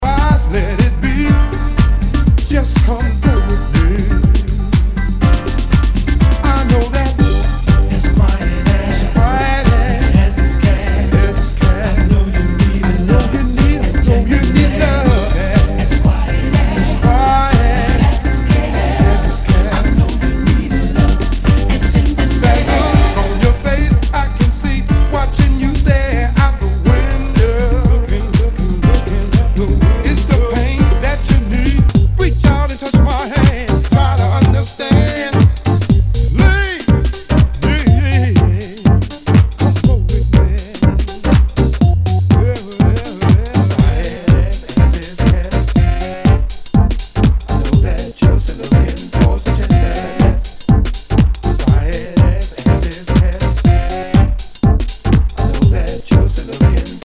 HOUSE/TECHNO/ELECTRO
ディープ・ヴォーカル・ハウス・クラシック！